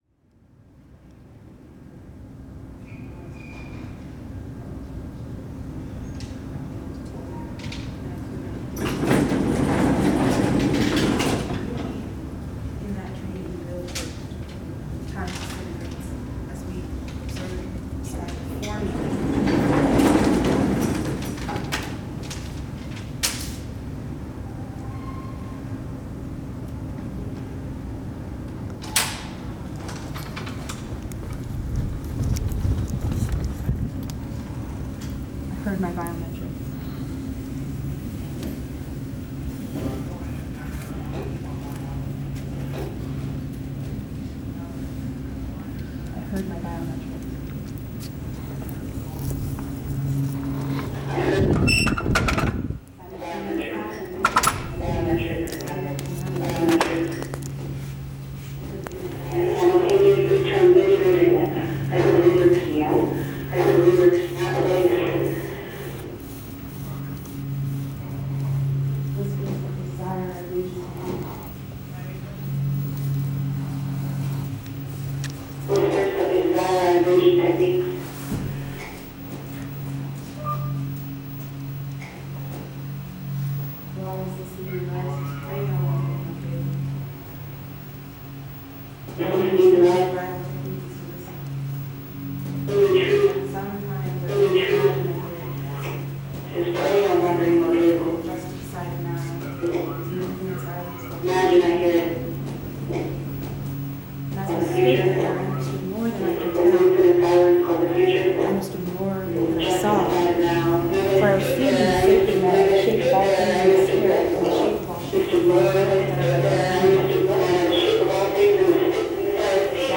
The performance system consisted of a hair-braid interface with movement, audio, video, sculpture, and text creating a spiral feedback dynamic between body and system.